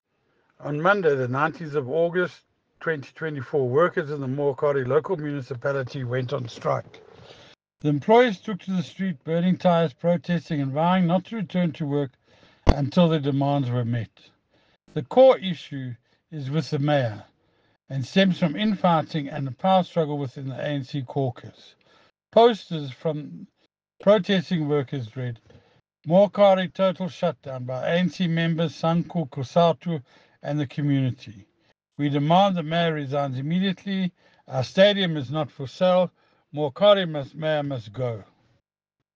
English soundbite by Cllr Ian Riddle and Sesotho by Jafta Mokoena MPL.